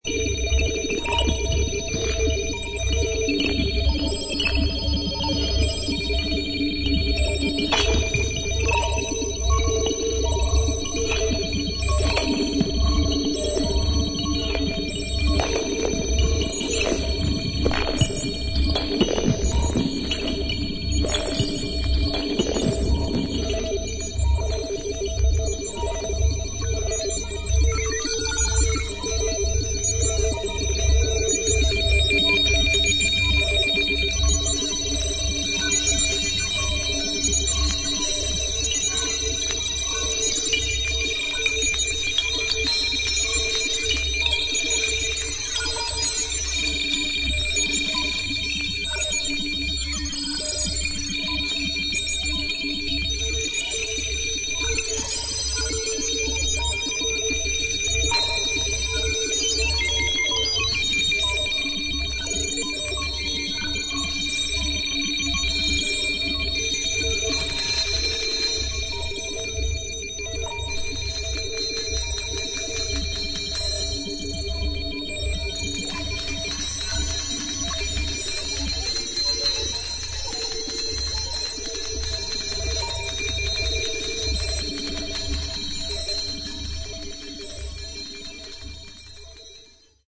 シンプルな編成ながら緻密で、まるで生きもののような深みを感じるエレクトロニクスの音像が堪能できます。